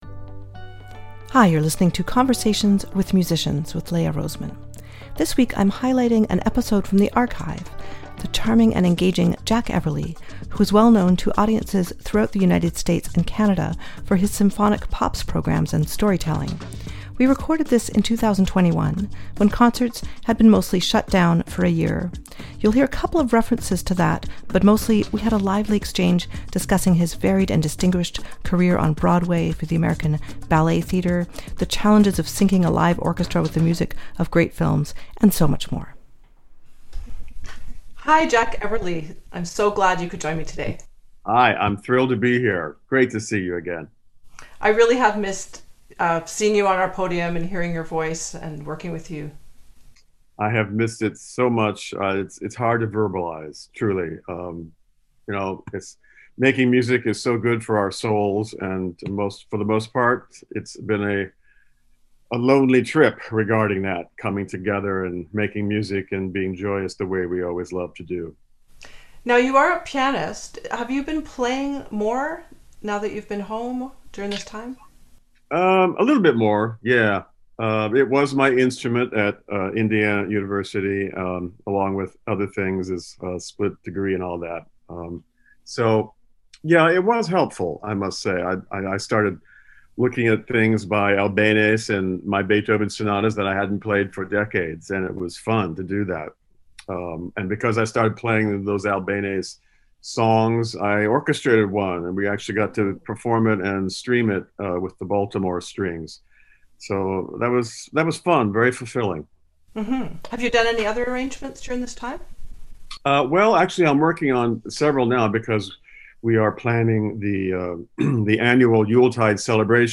We recorded this in 2021 when concerts had been mostly shut down for a year.